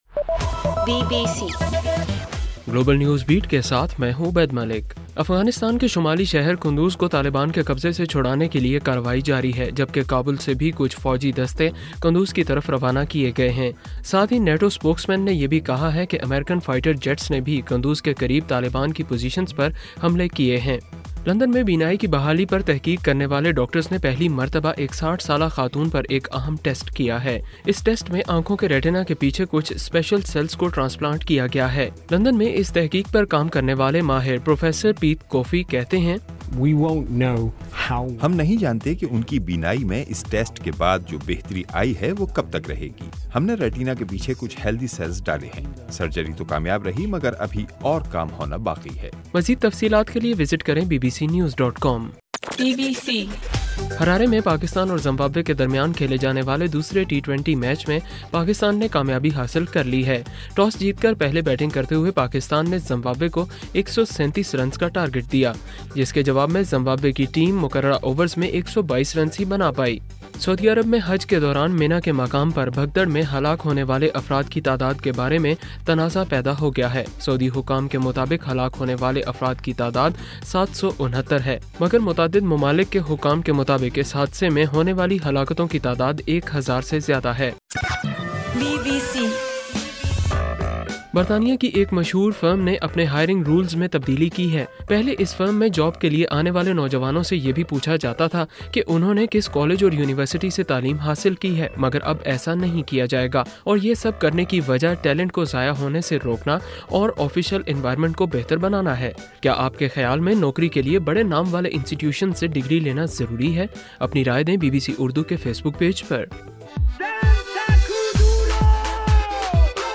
ستمبر 29: رات 9 بجے کا گلوبل نیوز بیٹ بُلیٹن